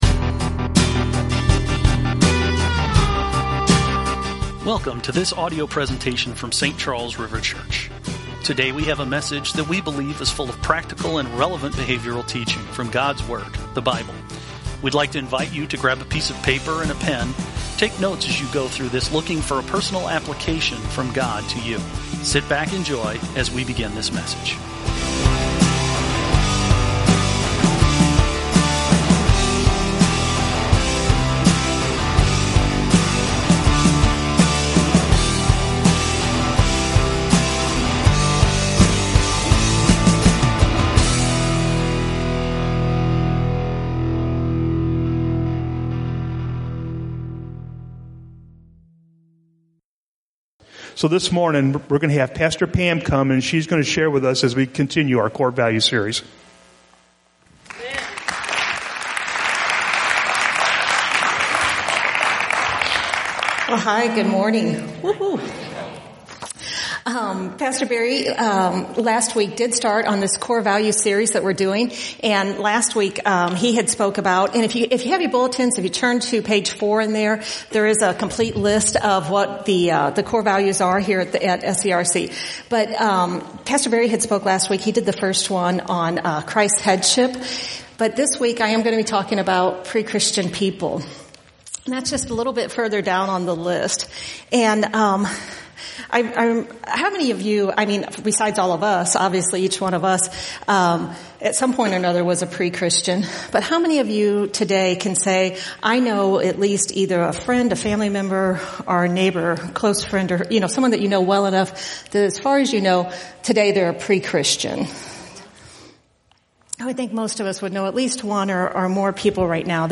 Weekly podcast recorded Sunday mornings during the Celebration Service at Saint Charles River Church in O'Fallon, Missouri.